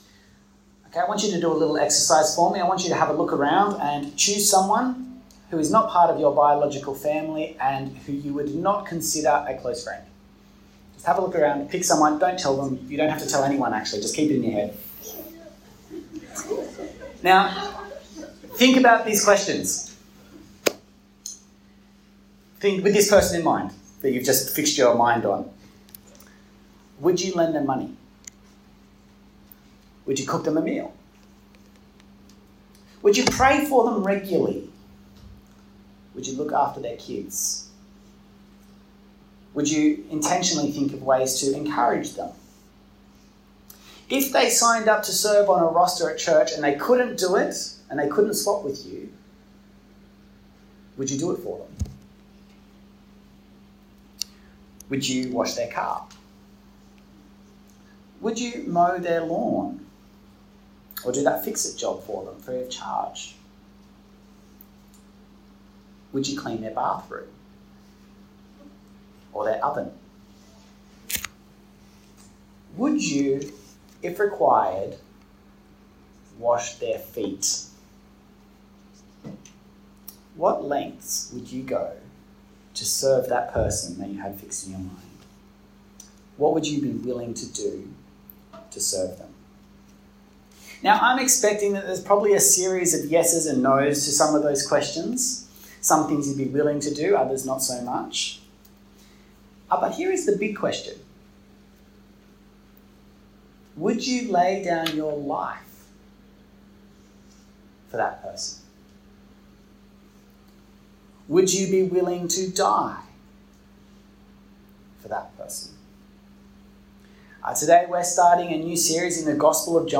John 13 1-30 Sermon.mp3